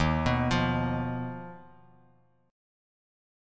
D#sus2#5 Chord